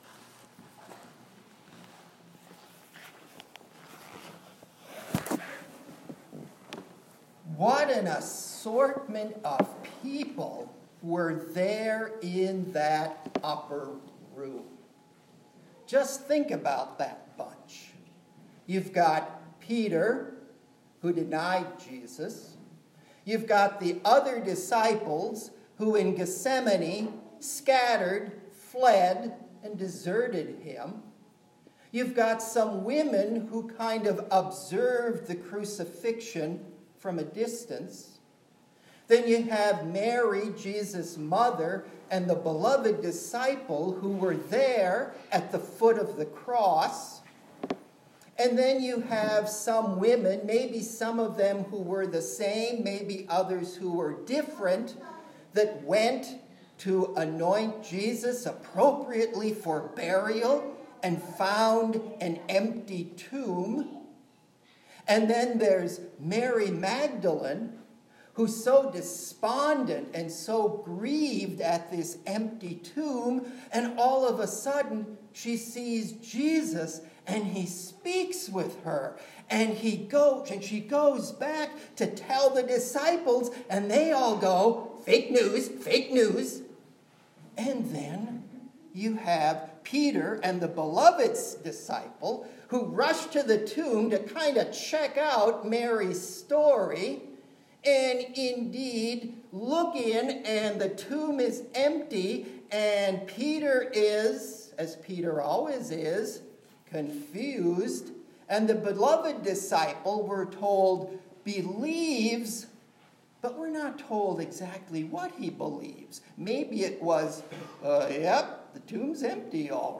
HOLDING FAST TO FORGIVENESS: A Sermon